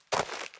footstep_dirt_road.ogg